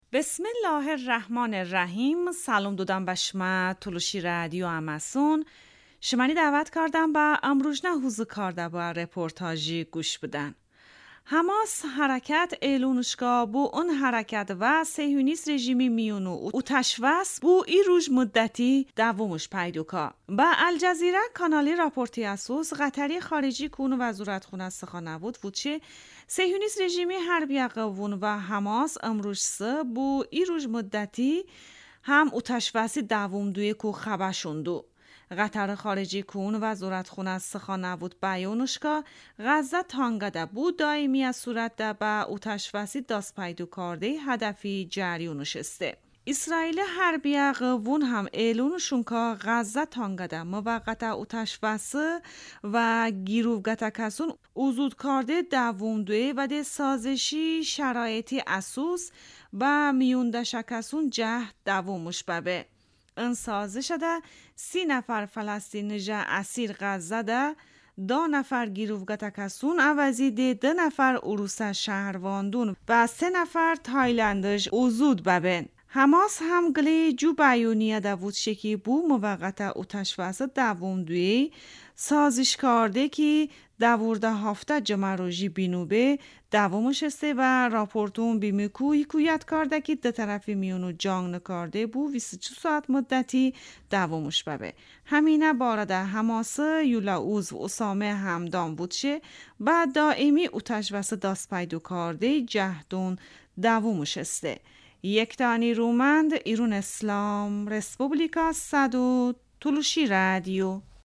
Tolışi rədiyo ım rujnə reportaj